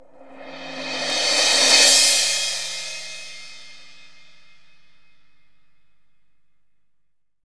Drums/CYM_NOW! Cymbals